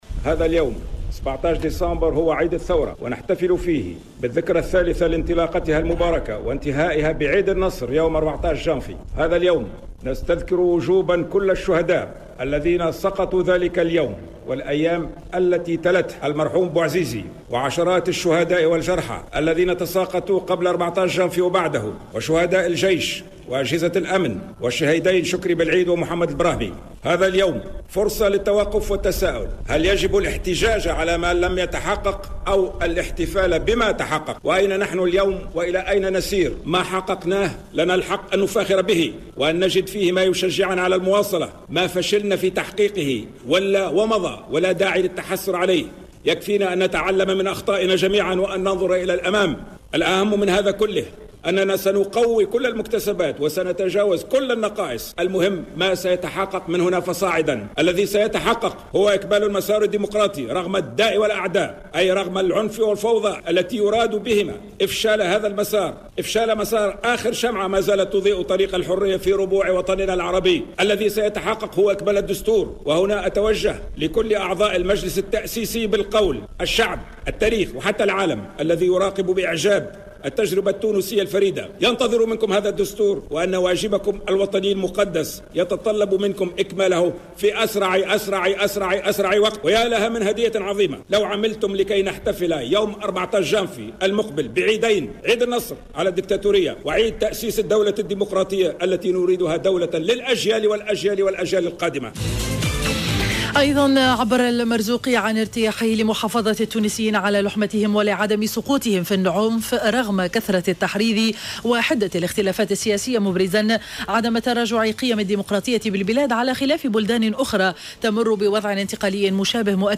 صرح رئيس الجمهورية المؤقت محمد المنصف المرزوقي في كلمة ألقاها اليوم بمناسبة الذكرى الثالثة لاندلاع الثورة ، ان هذا اليوم فرصة للتوقف على مدى ما حقنناه من نجاحات ونسيان الخيبات والتعلم من الأخطاء واستكمال المسار الديمقراطي رغم الداء والأعداء .